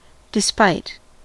Ääntäminen
IPA : /dɪˈspaɪt/